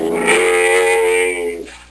c_camel_bat1.wav